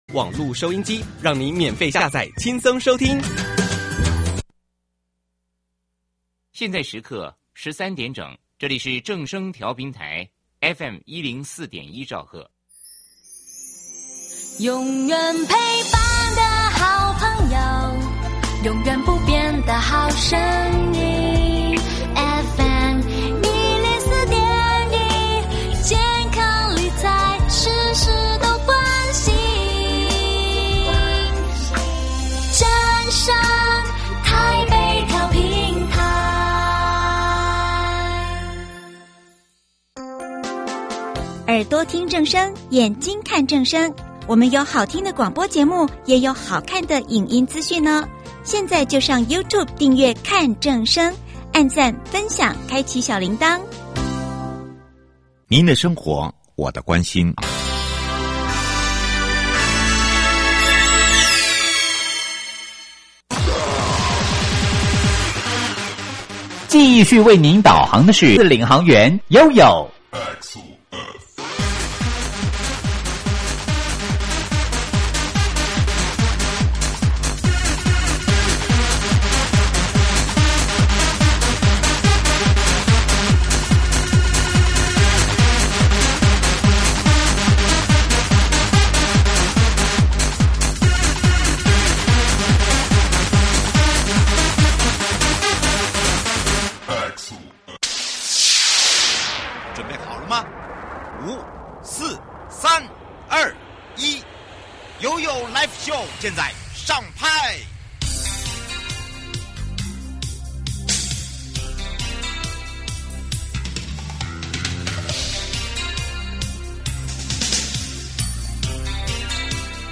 新竹縣政府交通旅遊處，持續推動各項「行人優先」的改善計畫，從學校、傳統市場，到廟宇周邊，每一個轉角，都藏著縣府滿滿的用心。今天，我們特別邀請到新竹縣政府交通旅遊處 陳盈州處長一起來聊聊這些貼近民眾生活的改變，看看我們的縣政團隊，如何用智慧、溫度與行動，讓行人更安全、城市更友善。